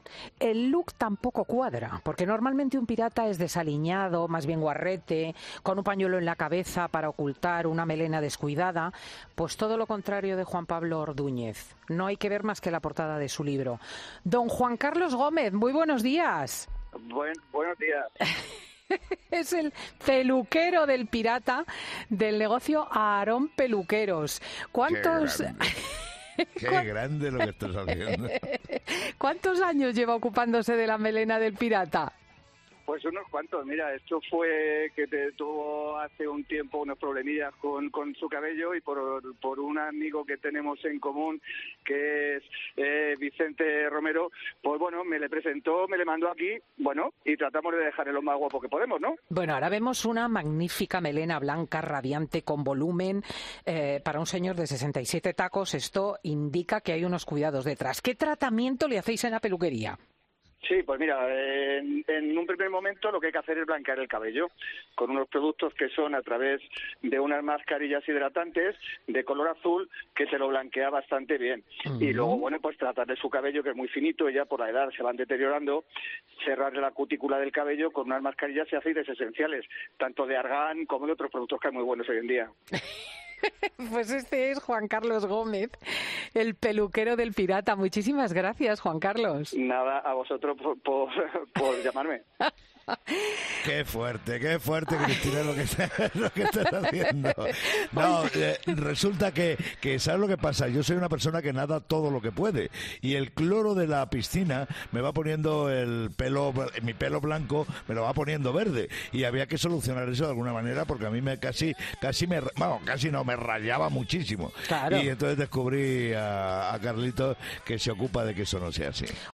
La llamada en directo que recibe El Pirata por la que "riñe" a Cristina López Schlichting: "Qué fuerte"
El Pirata entonces se quedaba boquiabierto y no daba crédito de a quién había llamado para contarlo todo sobre su melena, y llegaba a "reñirle" a la directora de 'Fin de Semana'.